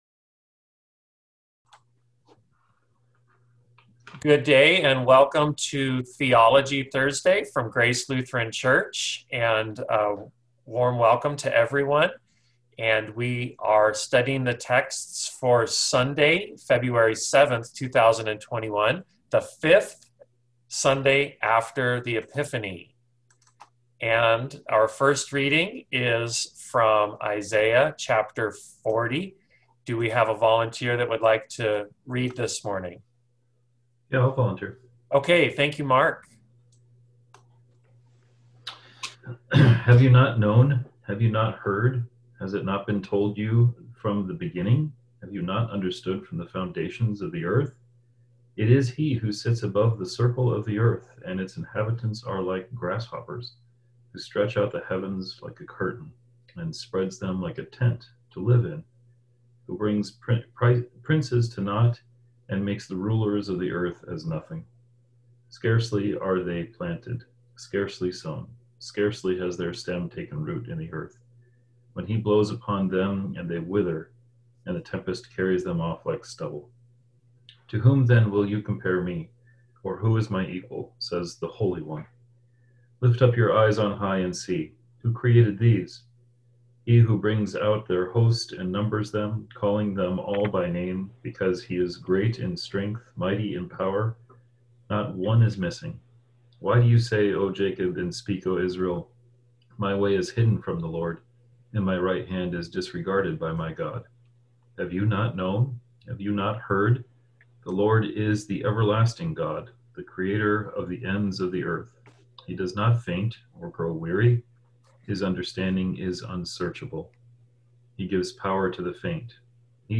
Bible Study for Thursday 4 February 2021 preparing for Sunday 7 February 2021, the fifth Sunday after Epiphany.